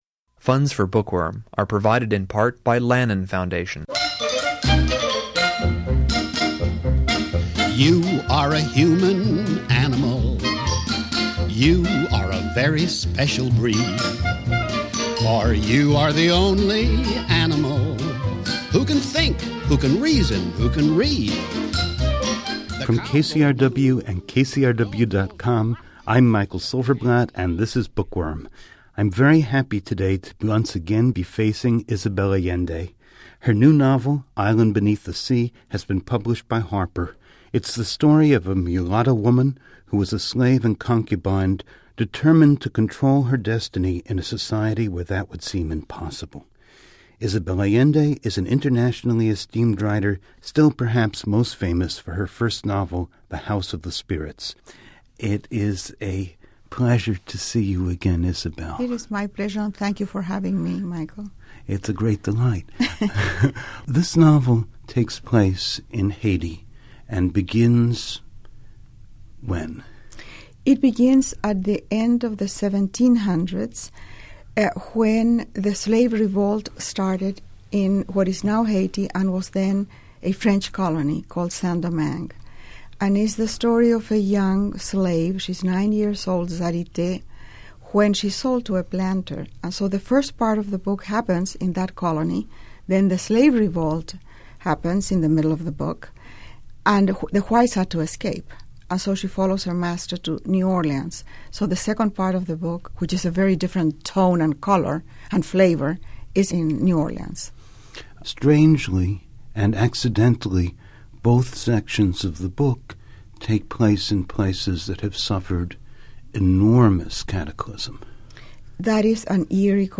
Isabel Allende's historical novel about slavery and the Haitian revolution becomes a springboard for a conversation about global injustice and the re-emergence of slavery.